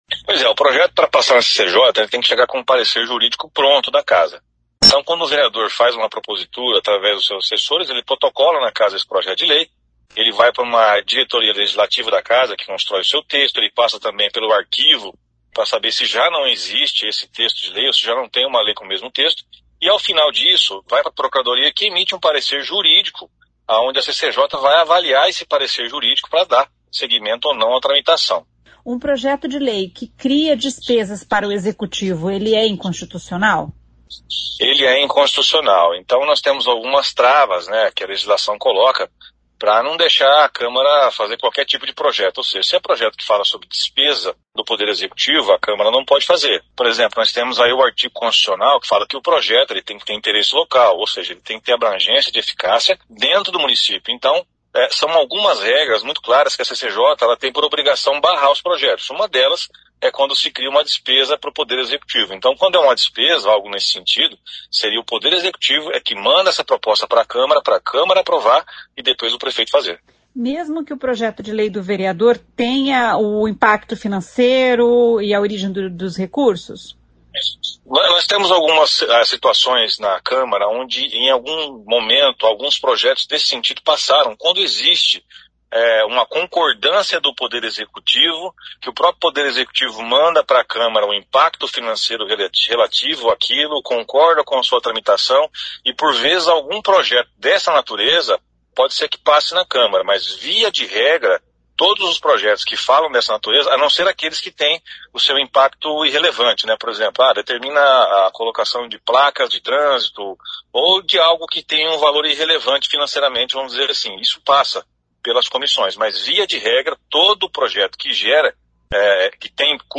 O presidente da CCJ, Flávio Mantovani, explica quais critérios um projeto precisa atender para passar pela comissão: